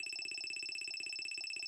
wheel_number_increasing_01.mp3